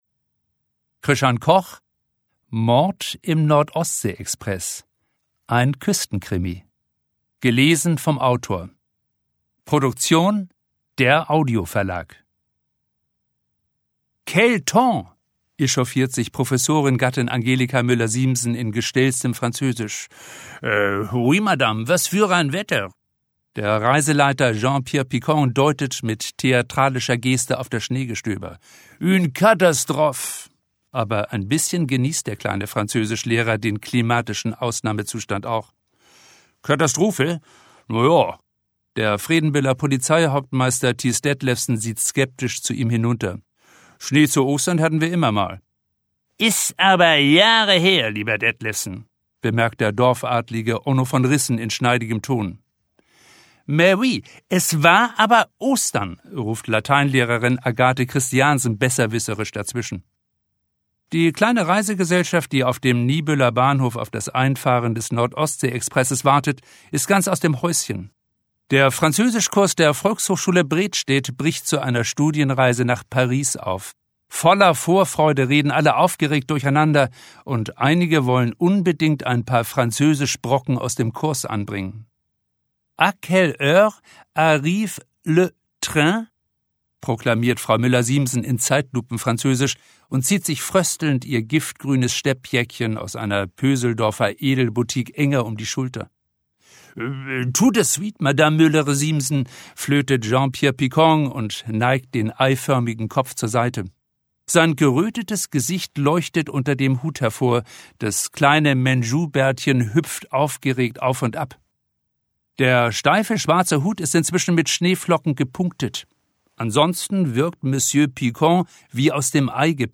Ungekürzte Autorenlesung